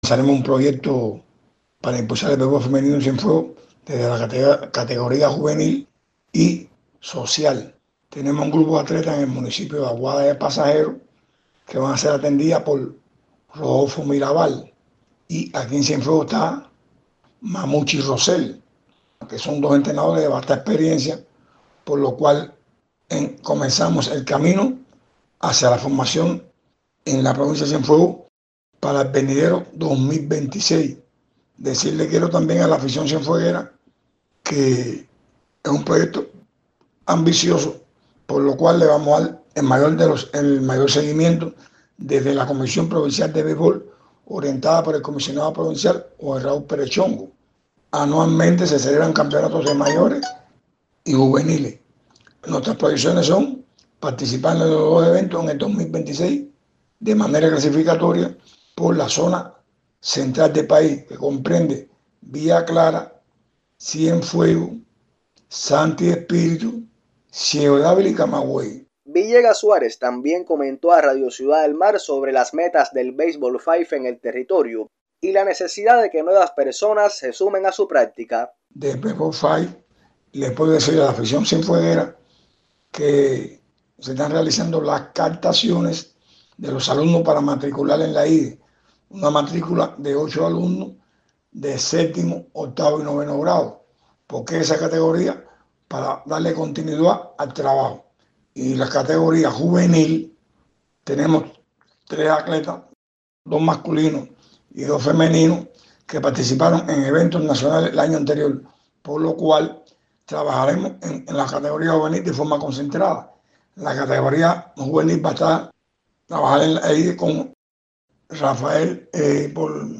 conversó con Radio Ciudad del Mar sobre los propósitos de dicho deporte y la estrategia para fomentar la práctica de béisbol femenino en el territorio.